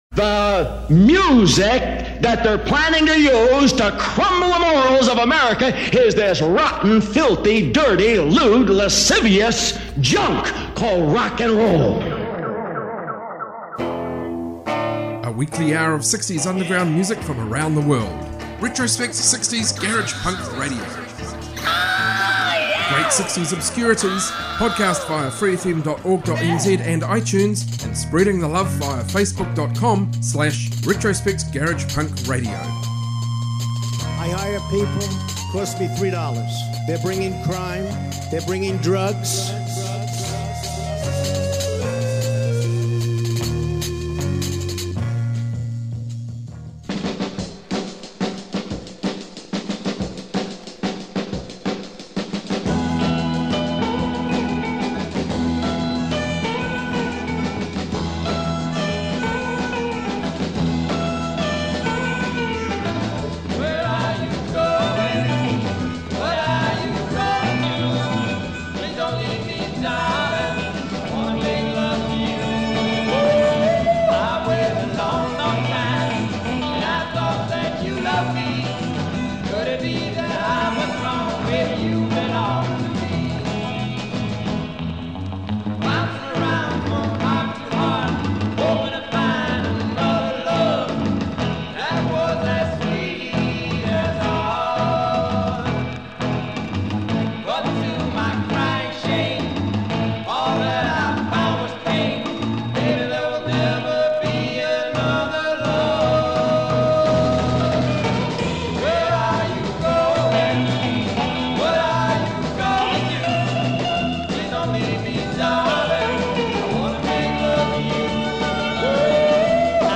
60s garage punk